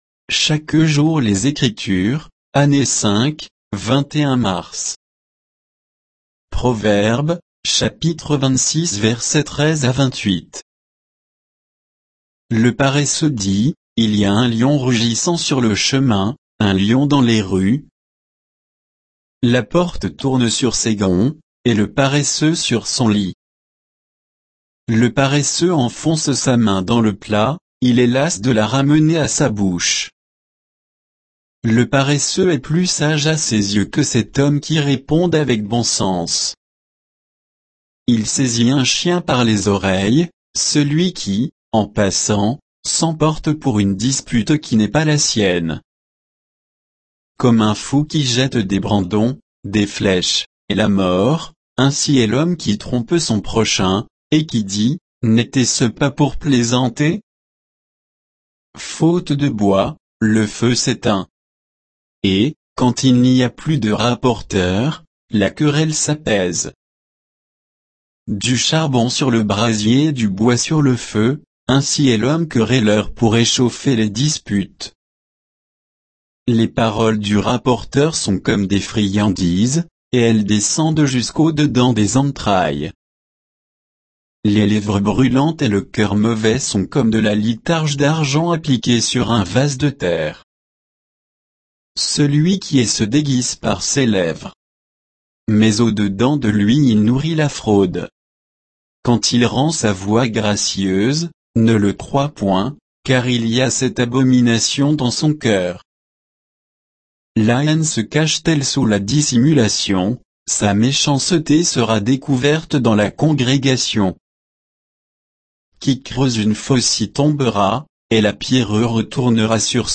Méditation quoditienne de Chaque jour les Écritures sur Proverbes 26, 13 à 28